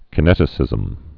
(kə-nĕtĭ-sĭzəm, kī-)